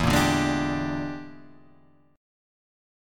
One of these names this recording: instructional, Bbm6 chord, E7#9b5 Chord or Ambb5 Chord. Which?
E7#9b5 Chord